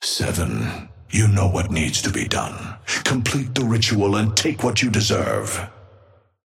Amber Hand voice line - Seven.
Patron_male_ally_gigawatt_start_01.mp3